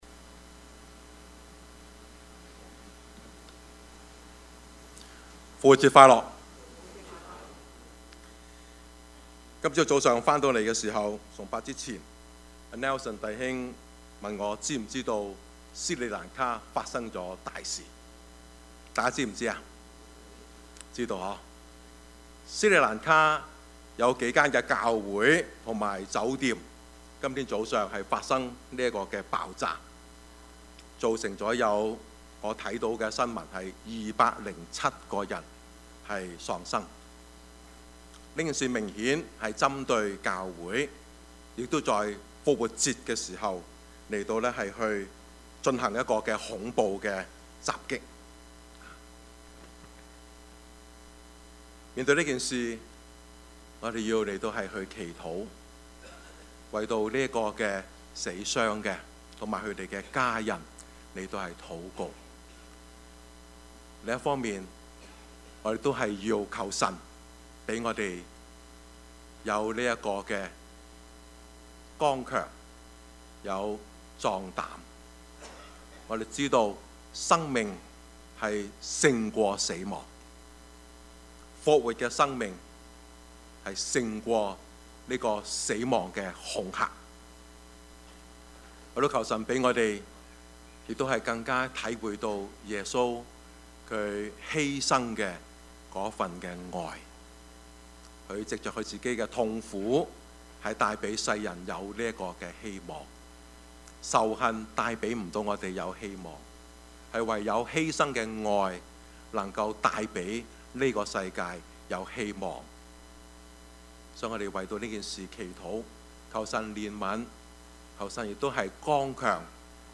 Service Type: 主日崇拜
Topics: 主日證道 « 教會是我家 教學相長 »